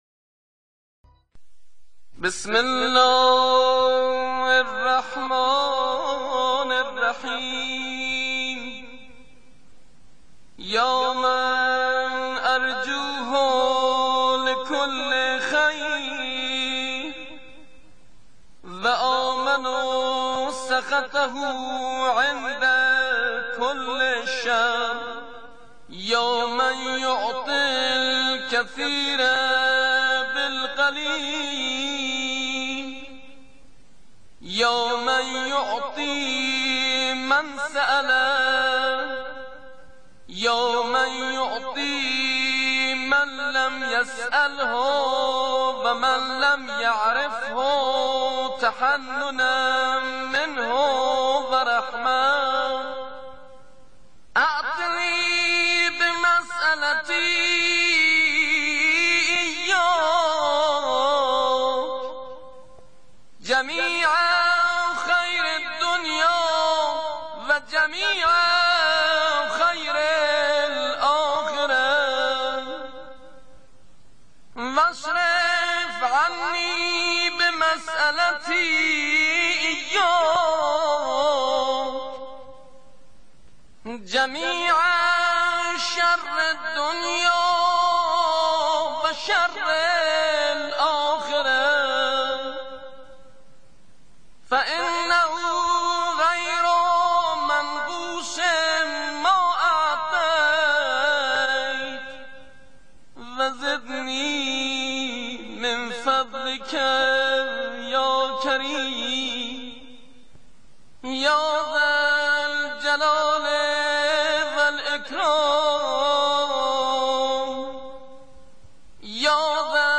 بیانات رهبری پیرامون دعای «یا مَن اَرجوُه لِکُلّ خَیر»